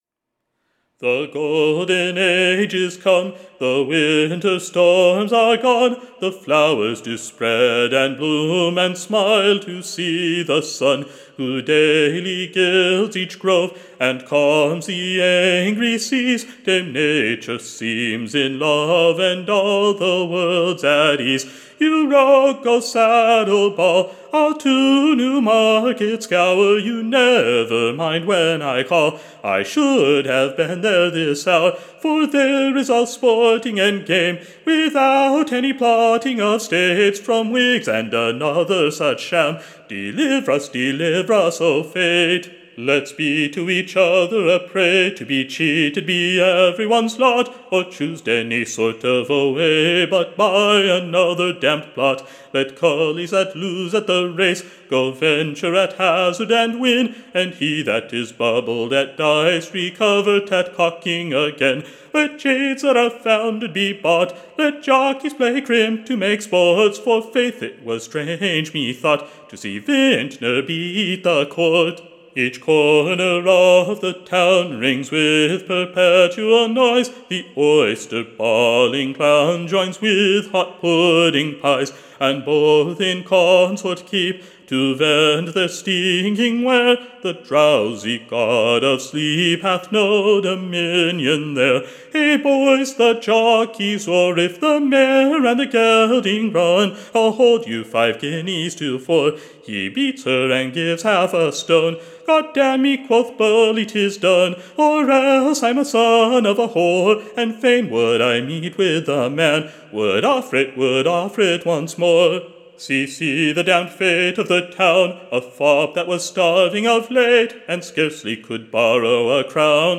Recording Information Ballad Title Sung before his Majesty at NEW-MARKET.